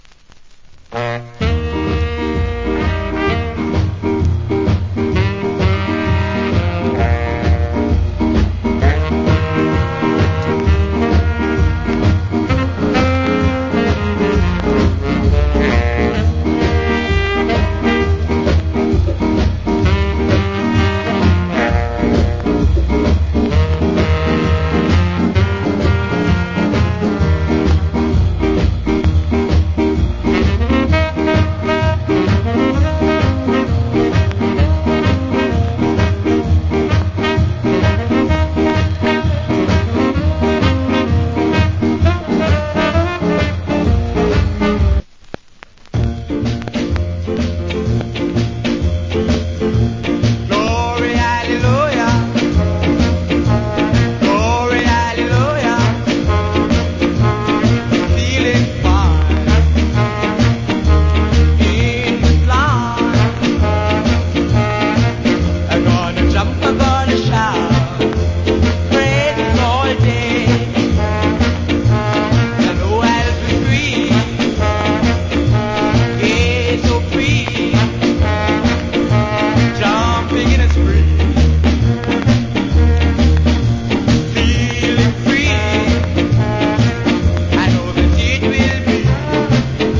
Cool Ska Inst.